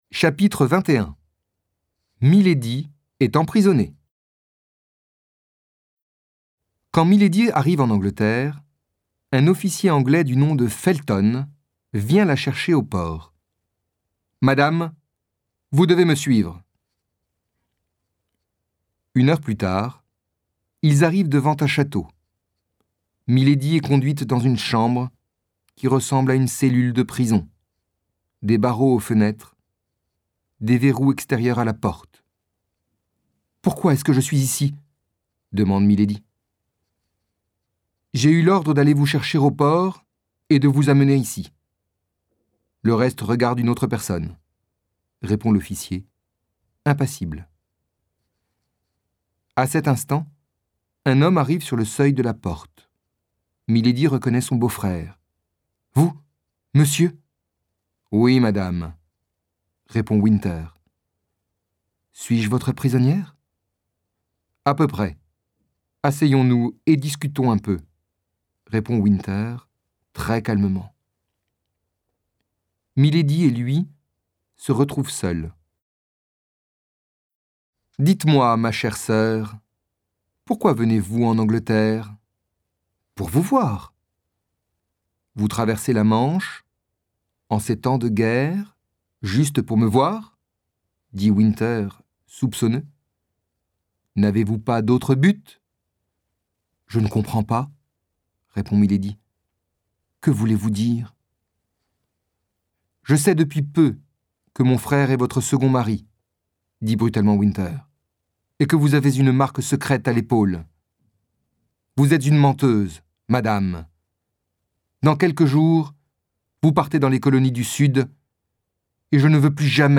Livre audio - Les Trois Mousquetaires